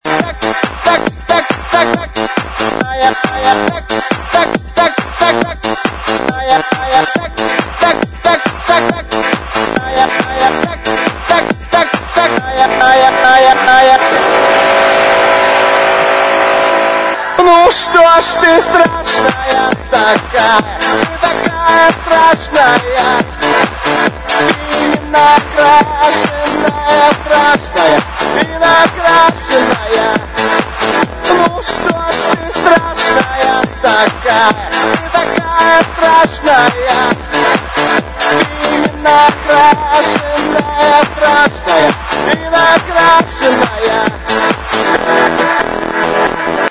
Список файлов рубрики Мр3 | House